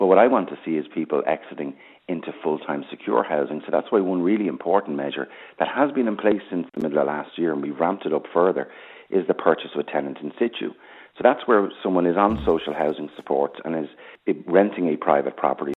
Minister O’Brien insists supports are in place to protect tenants: